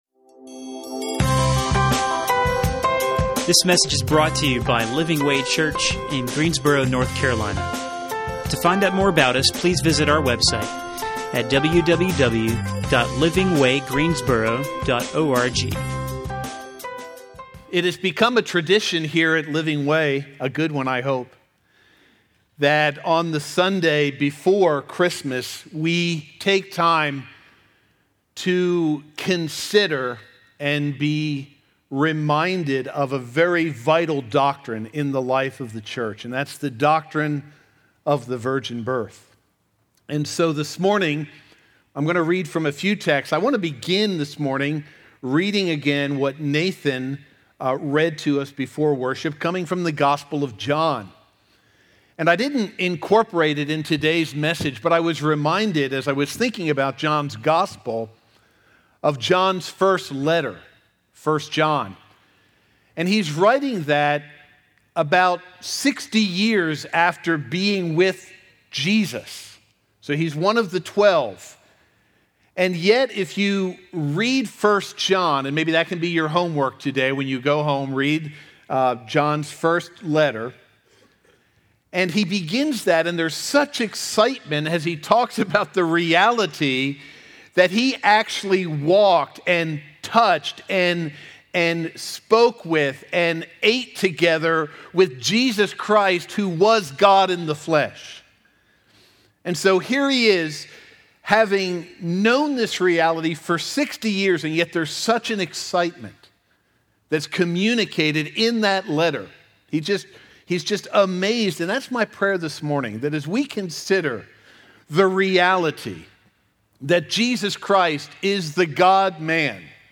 Stand Alone Sermons